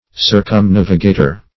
Circumnavigator \Cir`cum*nav"iga`tor\, n. One who sails round.